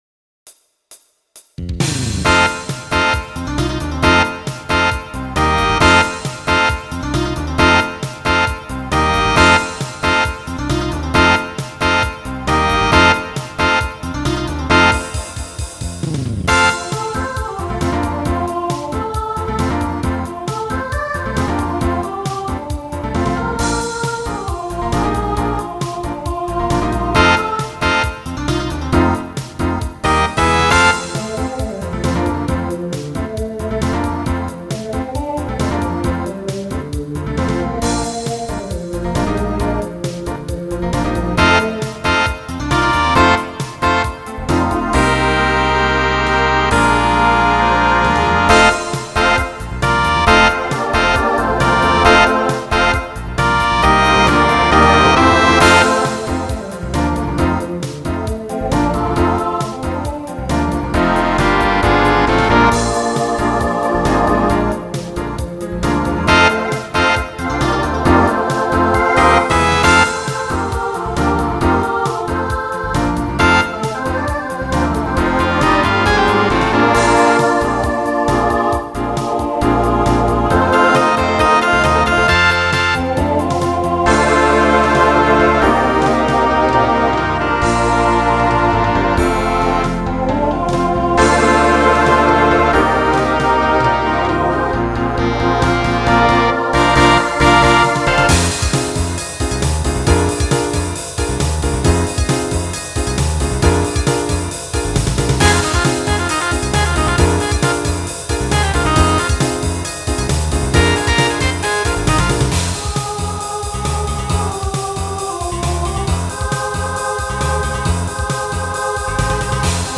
Voicing SATB Instrumental combo Genre Broadway/Film Show